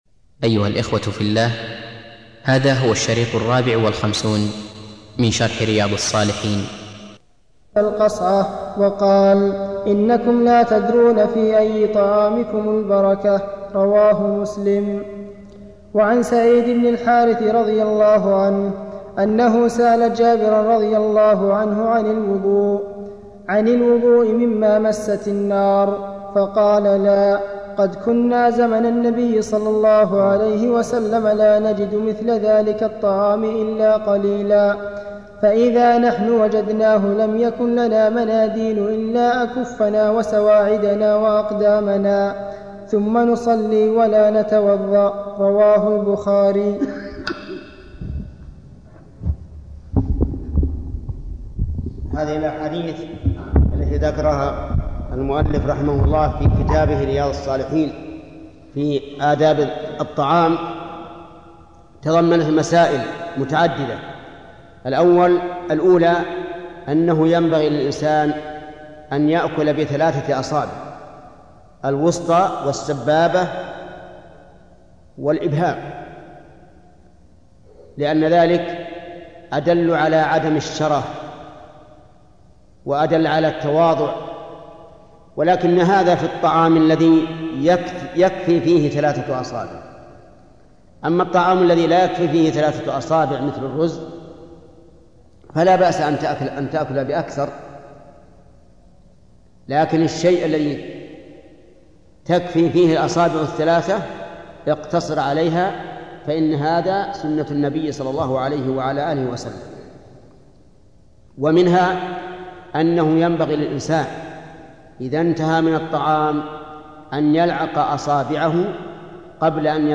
الدرس الحادي والأربعون